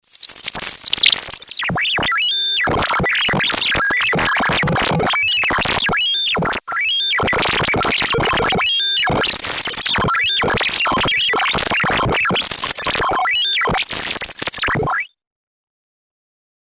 / F｜演出・アニメ・心理 / F-10 ｜ワンポイント マイナスイメージ_
マイナスイメージ 14 コンピューターエラー
ピュルルル